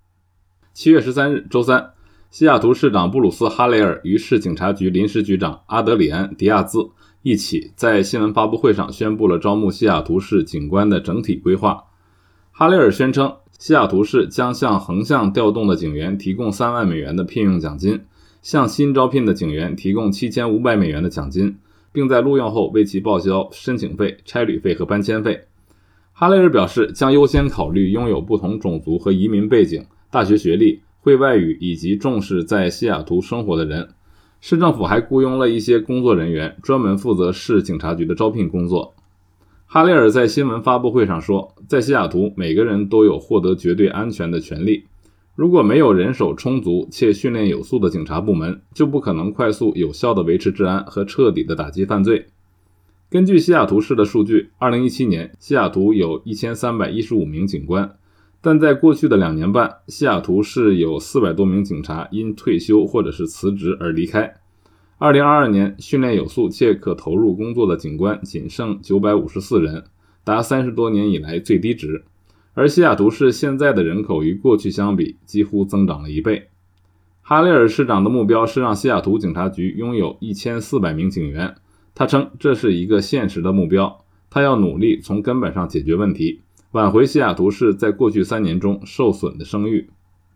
每日新聞